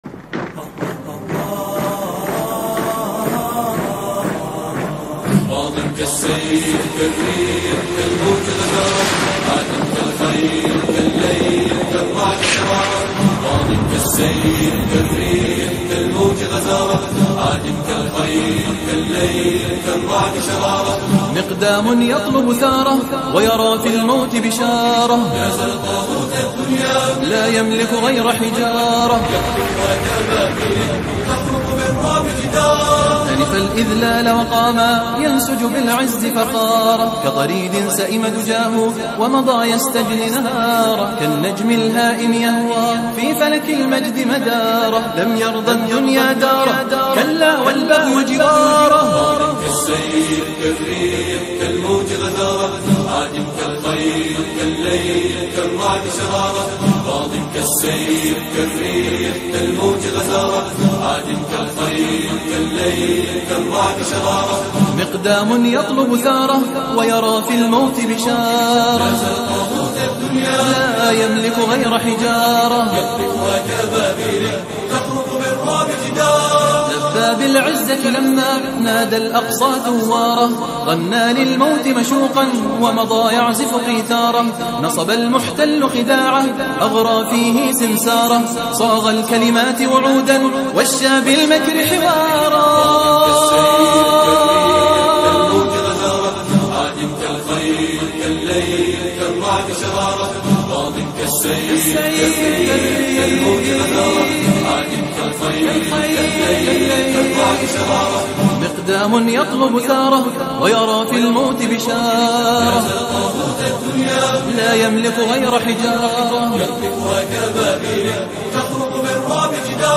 post nasheed
Islamic War Music - Motivation For Muslim Warriors.mp3 (3.25 MB)